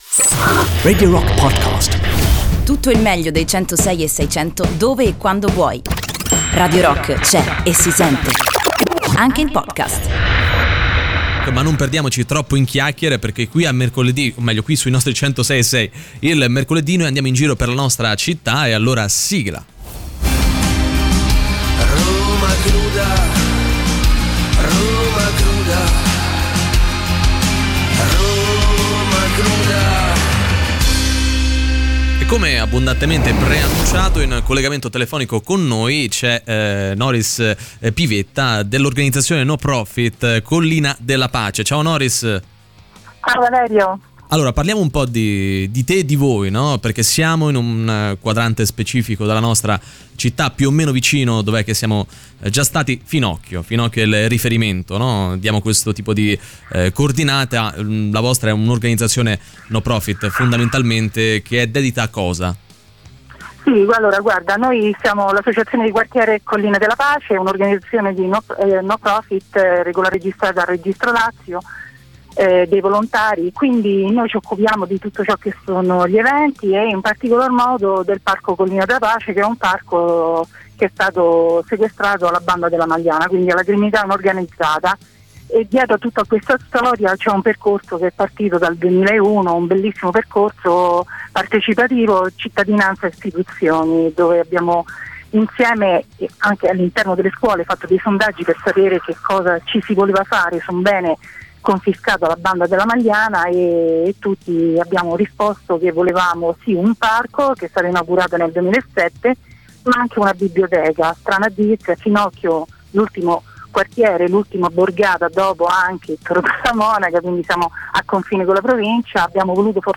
In diretta sui 106.6 di Radio Rock ogni mercoledì a partire dalle 15:30.